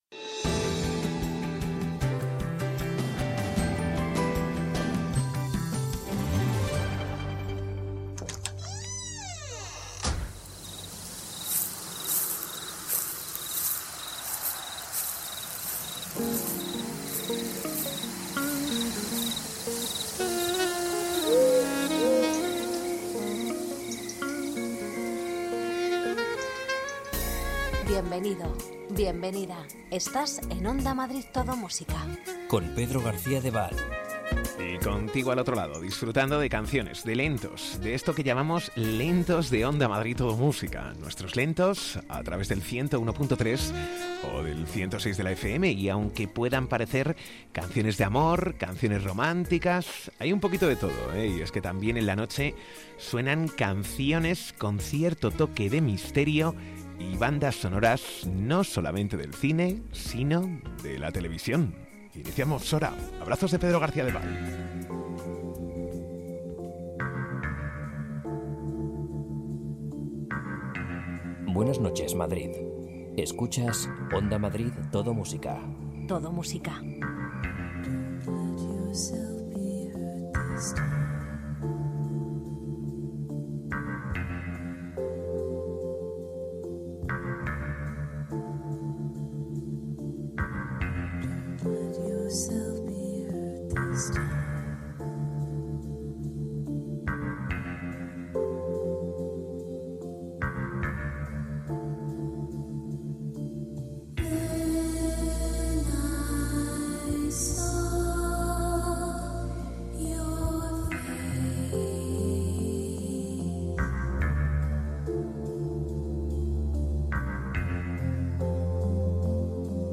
Ritmo tranquilo, sosegado, sin prisas...